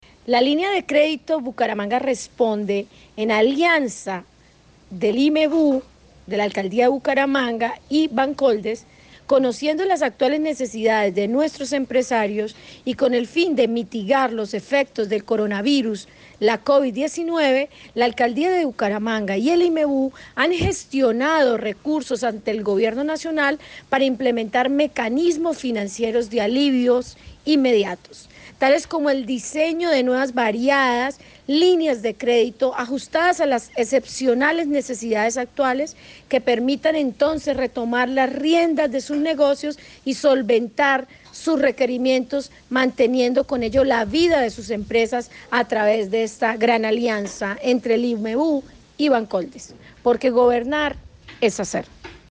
Isabel Cristina Rincón – Directora del IMEBU
Isabel-Cristina-Rincon-Directora-del-IMEBU-1.mp3